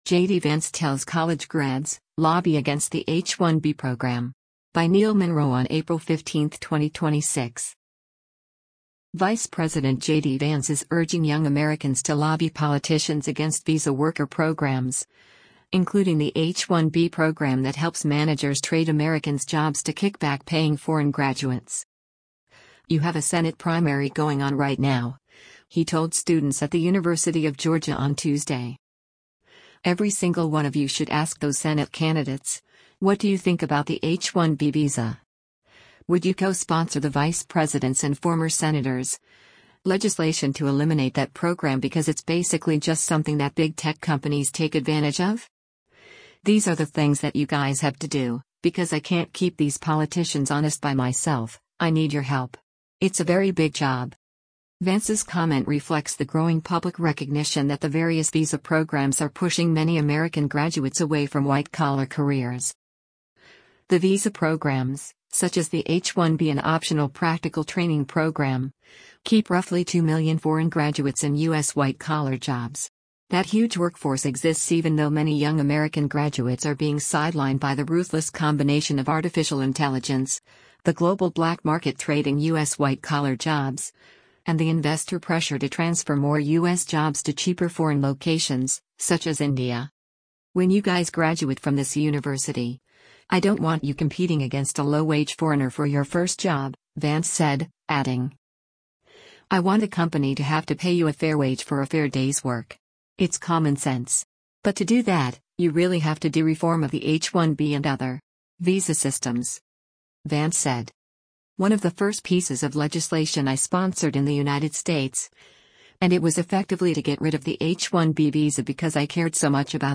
ATHENS, GEORGIA - APRIL 14: U.S. Vice President JD Vance speaks during a Turning Point USA